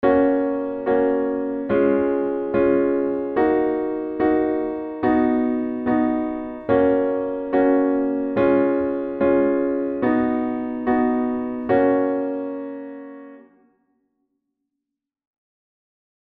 Akkordschema in Fis-Dur